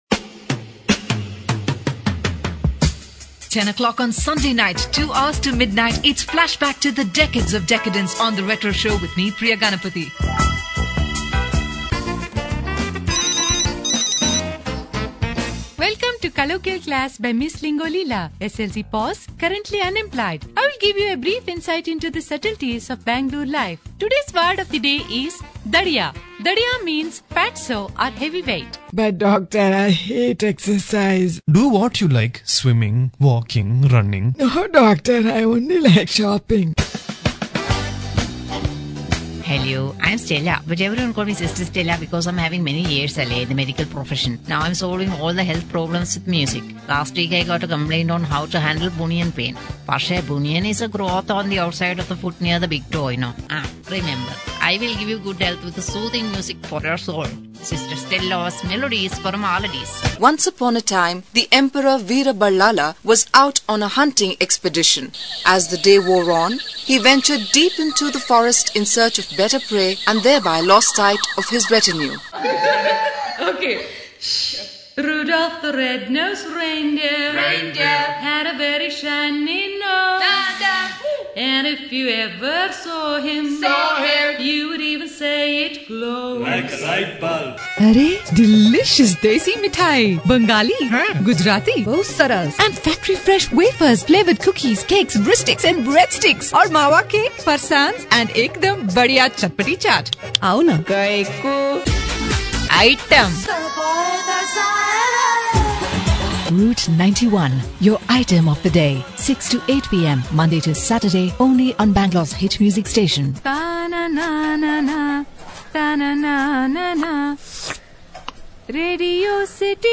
britisch
Sprechprobe: Werbung (Muttersprache):
female voice over talent english (uk).